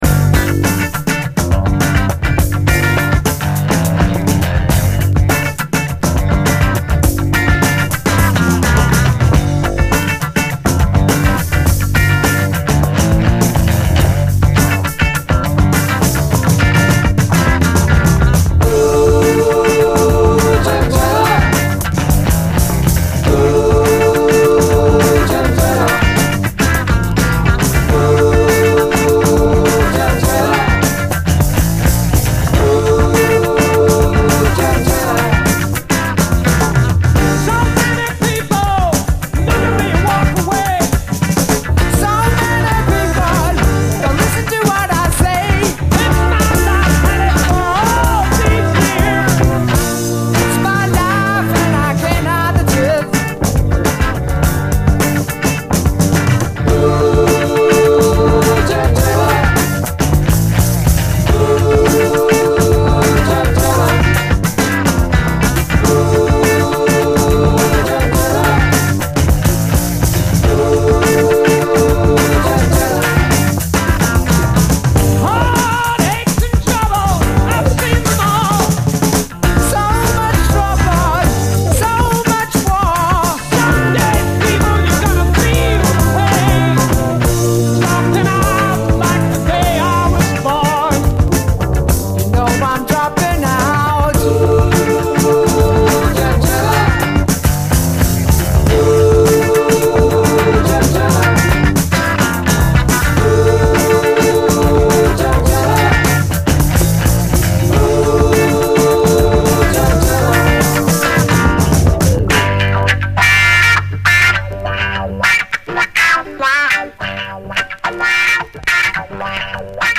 SOUL, 70's～ SOUL, 70's ROCK, ROCK
UK産70’Sファンキー・ロック！
中盤にはフルート・ブレイクもあり！